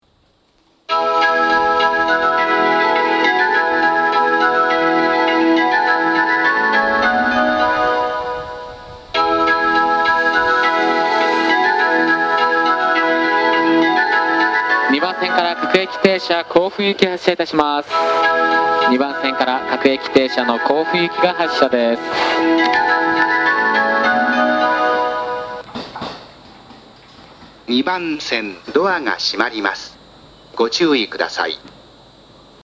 発車メロディー
3ターン（1.5コーラス）です!
快速は1ターンで切られることが多いです。